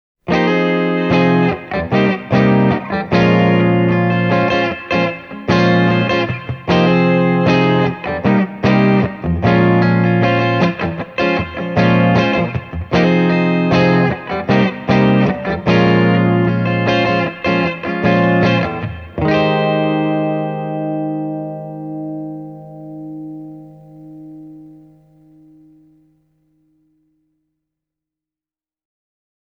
With clean settings Channel 1 will give you a fuller mid-range compared to the more Fender-like, chimey Channel 2.
…and here’s Channel 2 played with the same guitars: